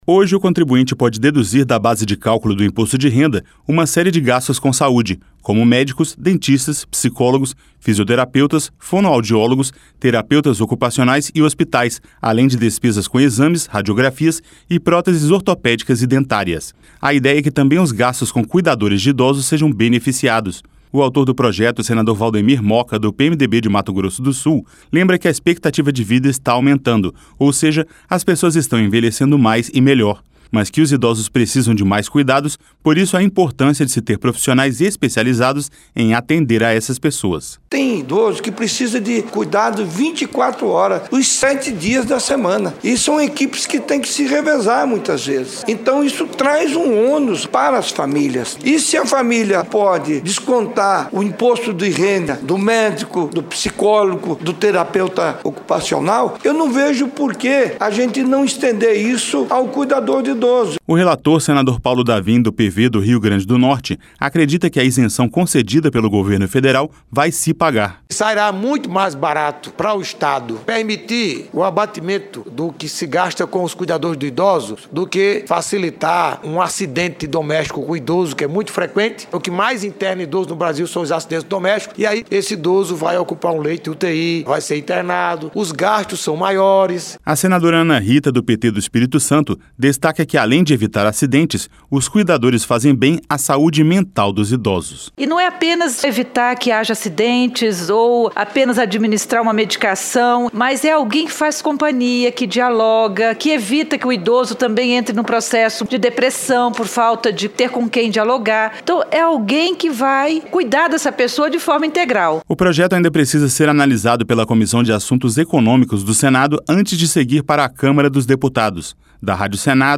O autor do projeto, senador Waldemir Moka, do PMDB de Mato Grosso do Sul, lembra que a expectativa de vida está aumentando, ou seja, as pessoas estão envelhecendo mais e melhor.